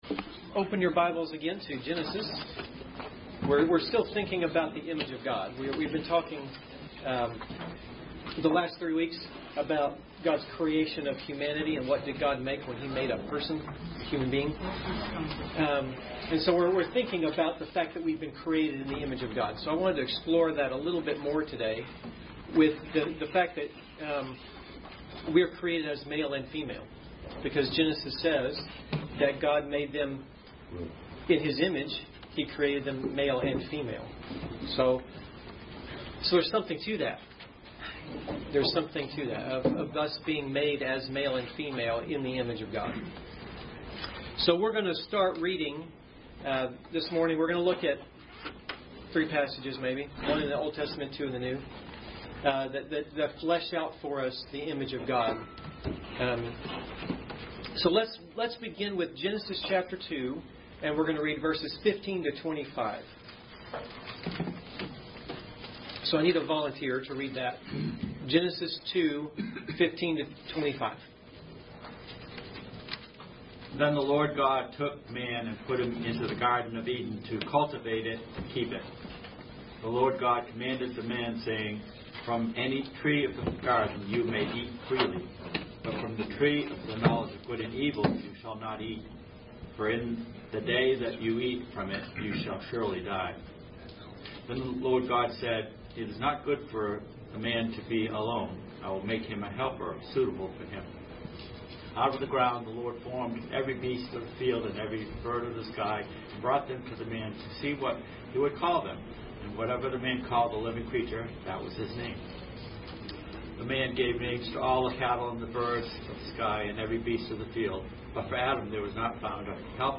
All Sermons Adult Sunday School September 22